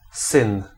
Ääntäminen
Ääntäminen Tuntematon aksentti: IPA: /sɨn/ Haettu sana löytyi näillä lähdekielillä: puola Käännös Ääninäyte 1. figlio {m} Suku: m .